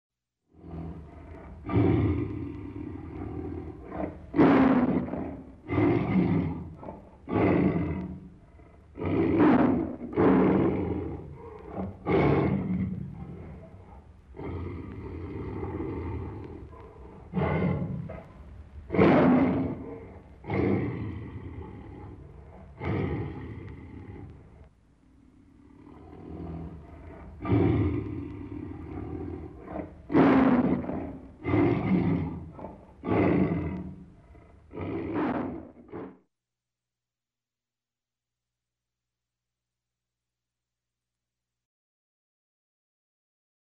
Voleu escoltar a un autèntic tigre?
tigre
rugido-de-tigre742.mp3